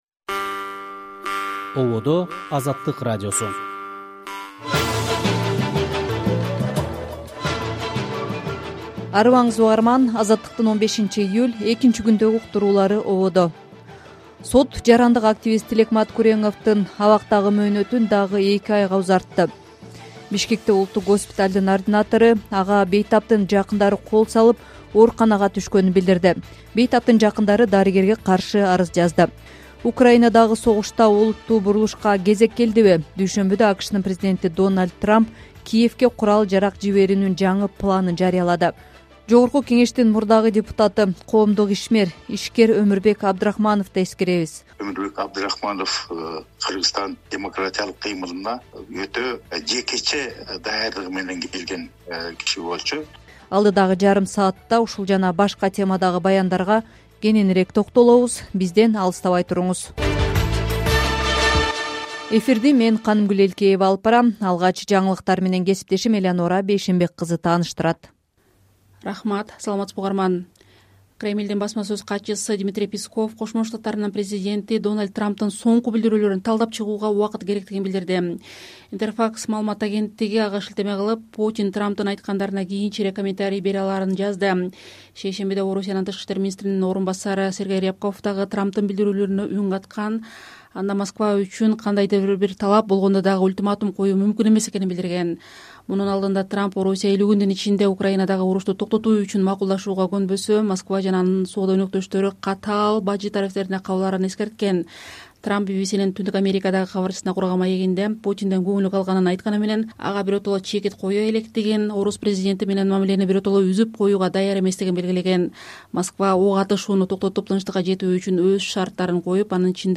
Азаттык: Кечки радио эфир | 15.07.2025 | Трамптын 50 күндүк эскертүүсү.